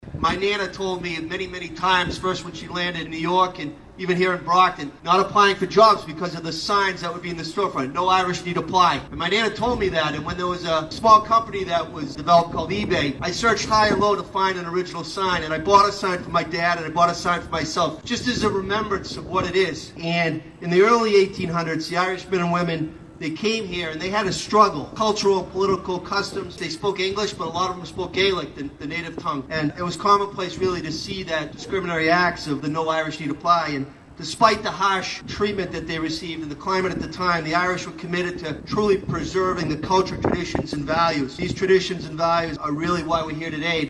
The Irish Flag was raised in front of Brockton City Hall as that country’s anthem played during a ceremony Thursday afternoon where Mayor Robert Sullivan discussed the challenges that many Irish immigrants, such as his ancestors, faced.